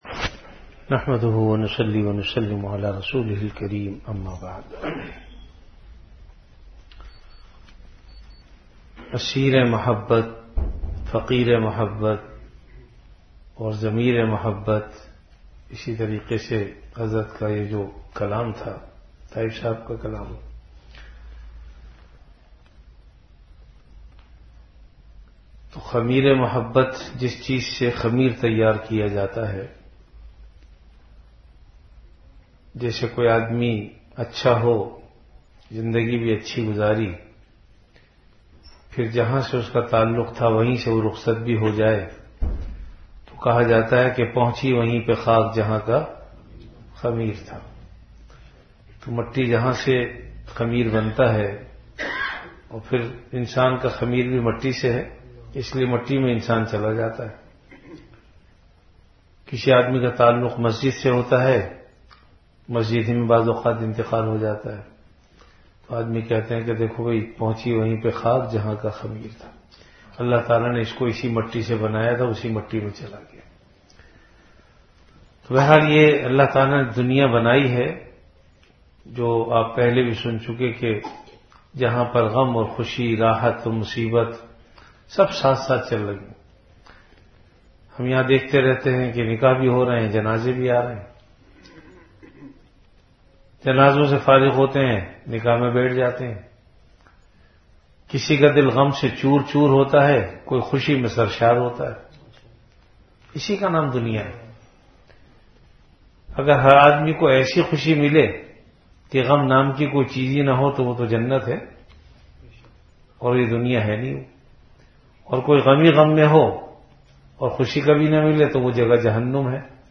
Category Majlis-e-Zikr
Venue Home Event / Time After Magrib Prayer